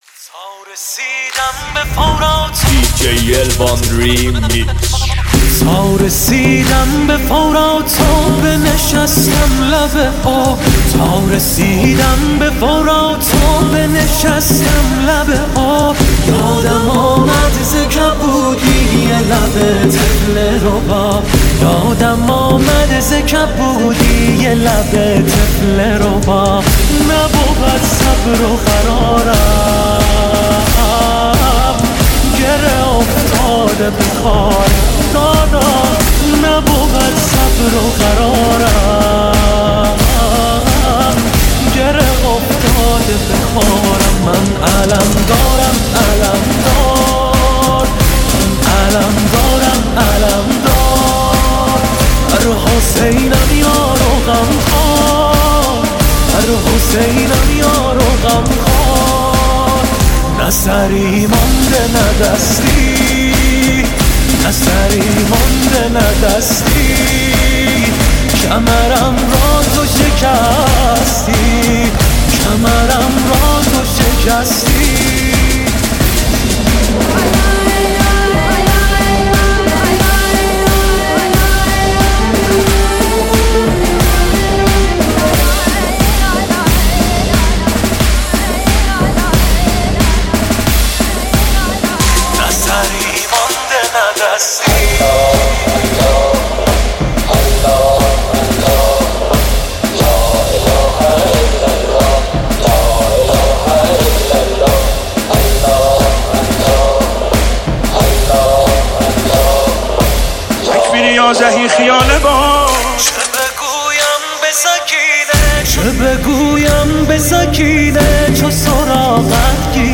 ریمیکس مداحی محرم